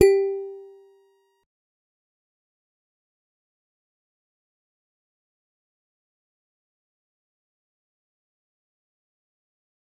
G_Musicbox-G4-mf.wav